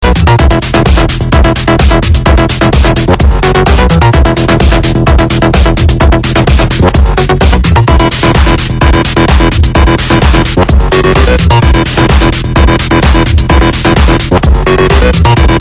Easy to id (electro) 2